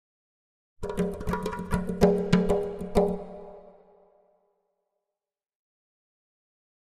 Drums Exotic Percussion Beat Finale 3